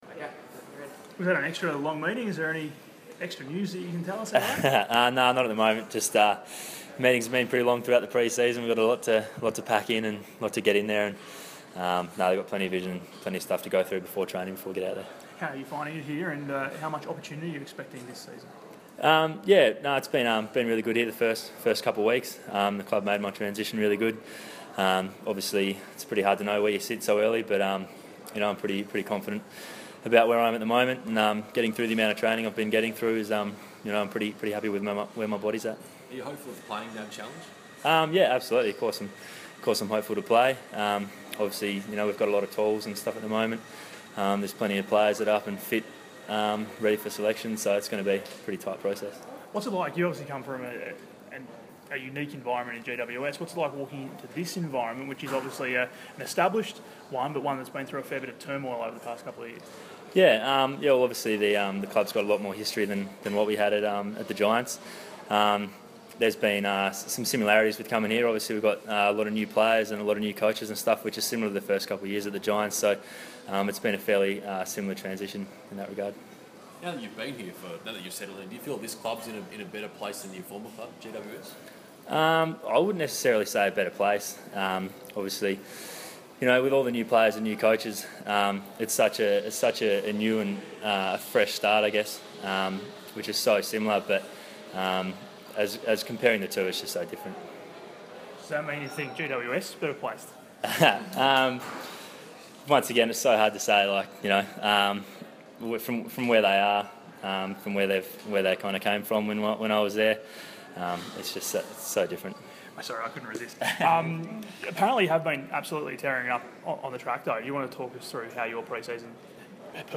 press conference
speaks to the media ahead of Wednesday’s training session at Ikon Park.